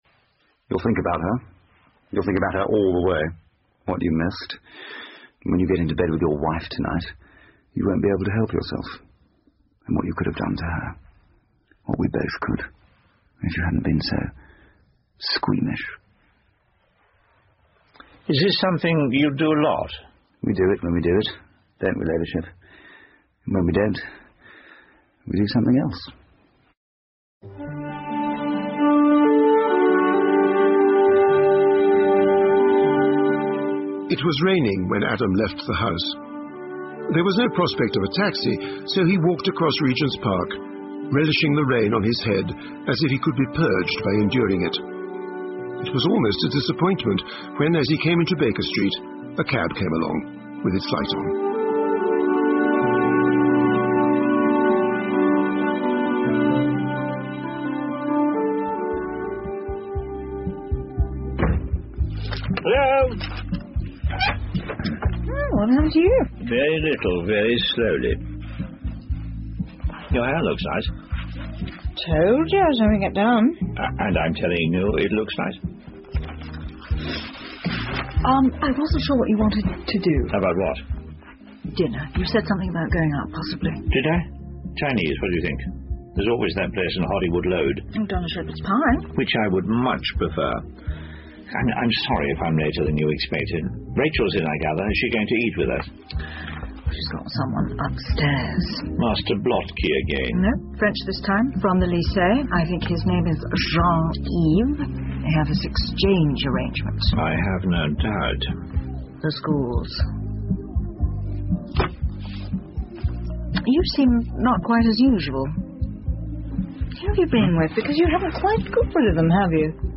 英文广播剧在线听 Fame and Fortune - 14 听力文件下载—在线英语听力室